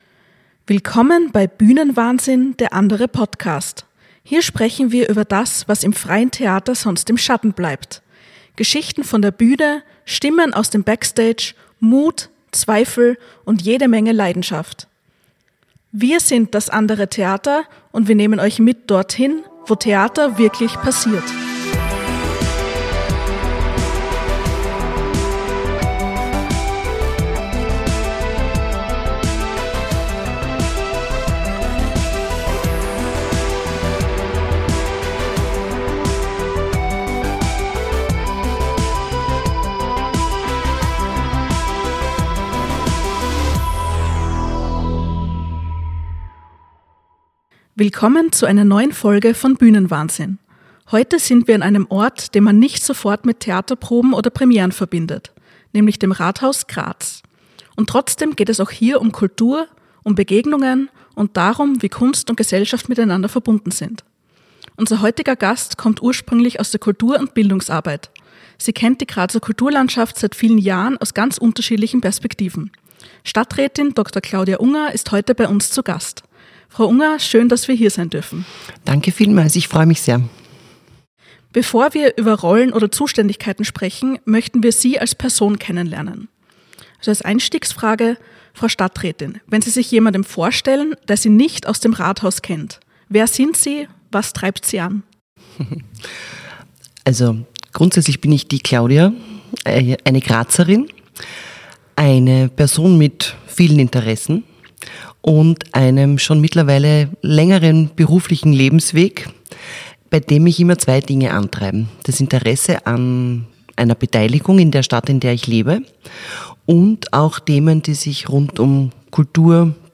In dieser Folge von Bühnenwahnsinn sind wir zu Gast im Grazer Rathaus und sprechen mit Claudia Unger, der neuen Stadträtin für Kultur.